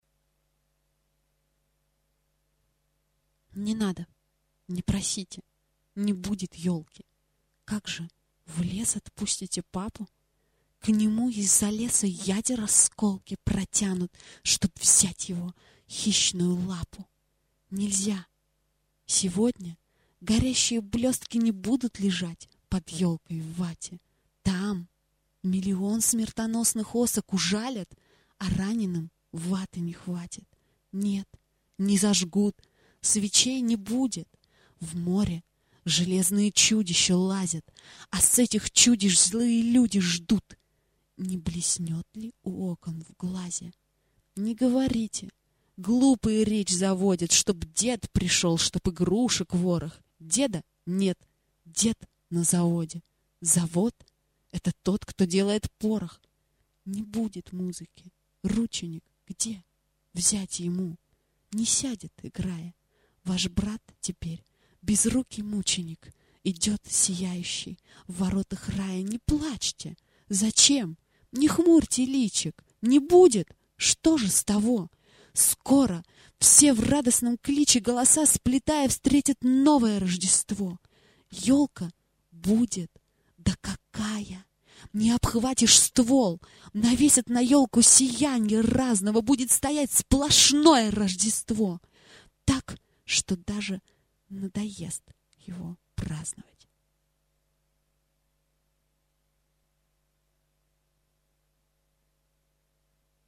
chitaet-lyubimogo-Mayakovskogo-Hvoi-stih-club-ru.mp3